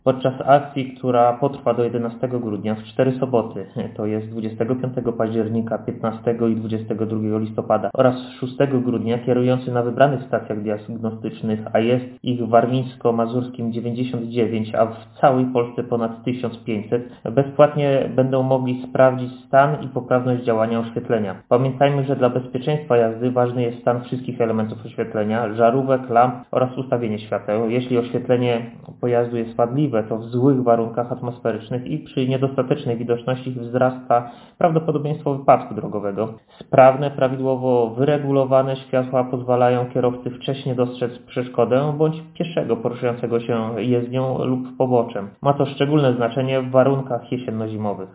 mówi Radiu 5